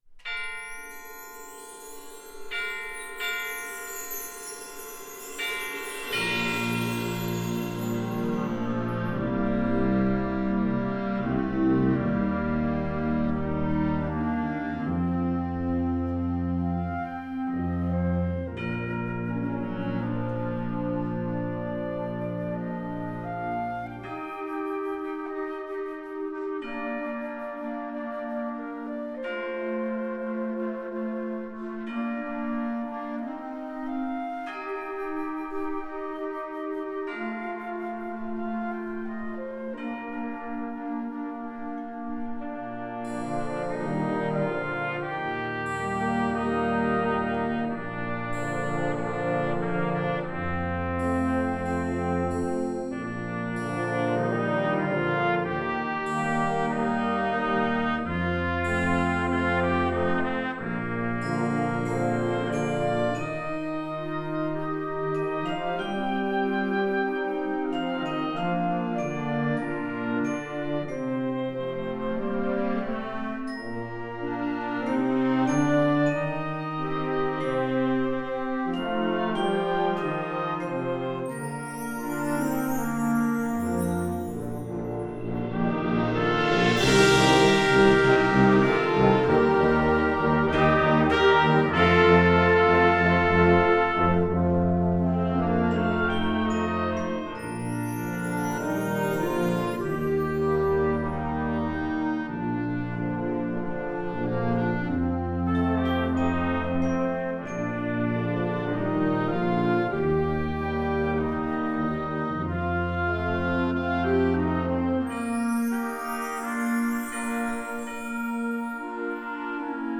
Gattung: Konzertwerk für Jugendblasorchester
Besetzung: Blasorchester
schöne Ballade